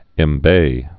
(ĕm-bā)